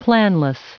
Prononciation du mot planless en anglais (fichier audio)
Prononciation du mot : planless